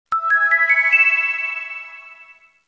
File Type : Sms ringtones